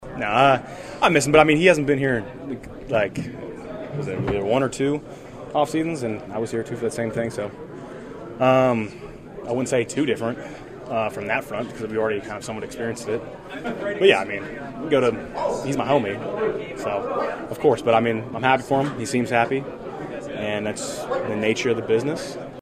In the post-practice locker room,  Bakhtiari was the star attraction.
David was exceedingly upbeat, animated and playful with reporters for nearly 30 minutes both on the record and off.    Here are some of the highlights, beginning with a question about if he feels any differently without his best friend, Aaron Rodgers in the locker room.